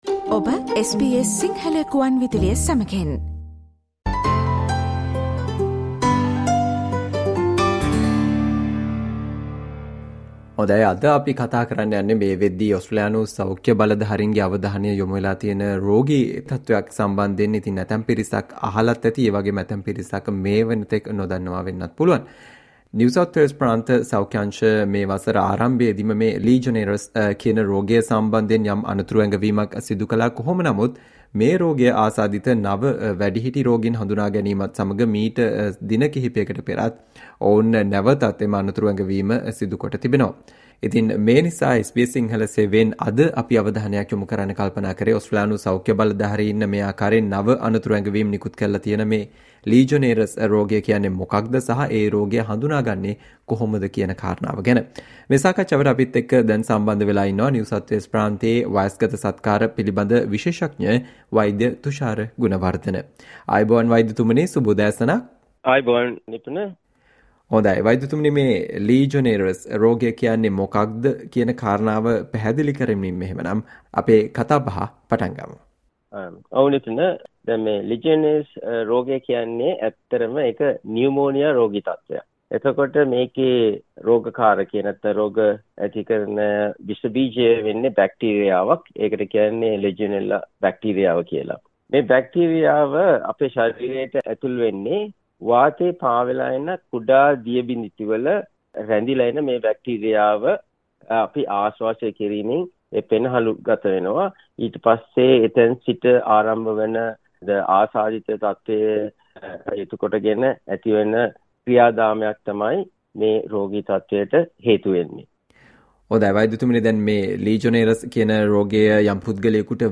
Listen to the SBS Sinhala discussion on how to avoid legionnaires disease